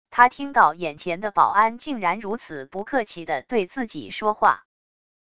他听到眼前的保安竟然如此不客气的对自己说话wav音频生成系统WAV Audio Player